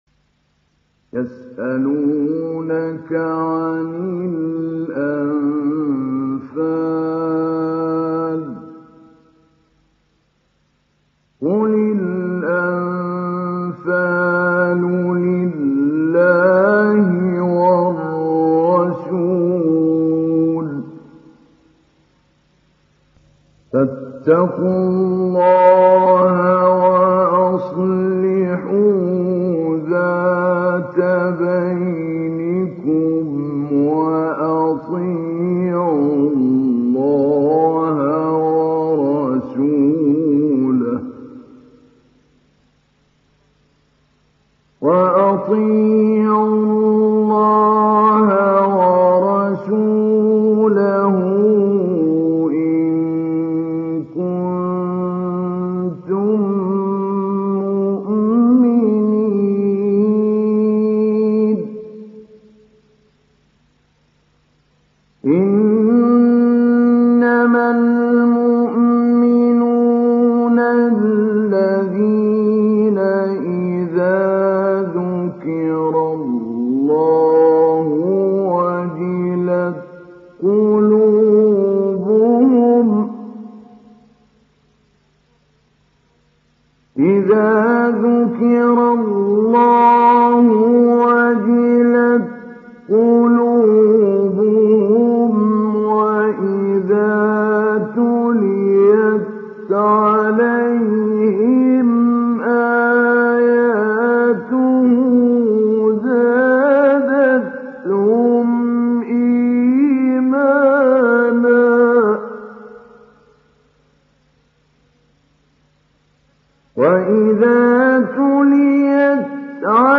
Surat Al Anfal Download mp3 Mahmoud Ali Albanna Mujawwad Riwayat Hafs dari Asim, Download Quran dan mendengarkan mp3 tautan langsung penuh
Download Surat Al Anfal Mahmoud Ali Albanna Mujawwad